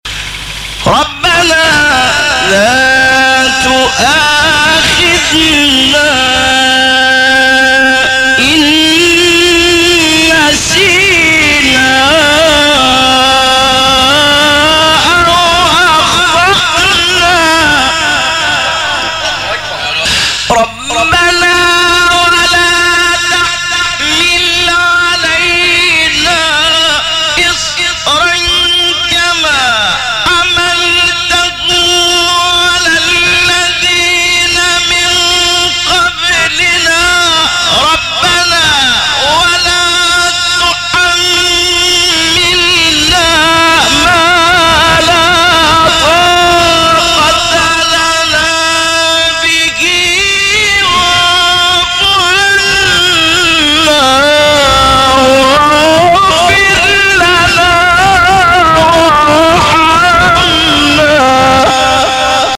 فرازهای صوتی از قاریان به‌نام مصری